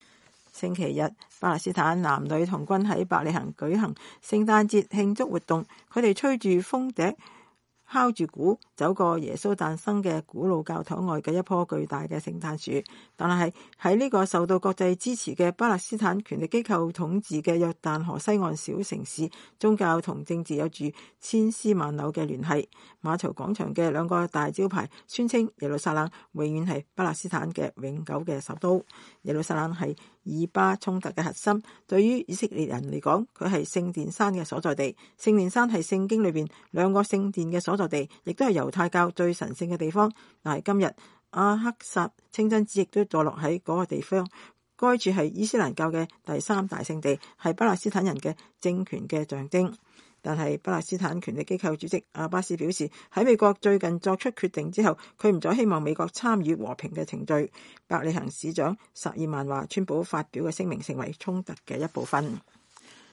星期天，巴勒斯坦男女童軍在伯利恆舉行聖誕節慶祝活動。他們吹著風笛敲著鼓，走過耶穌誕生的古老教堂外的一棵巨大的聖誕樹。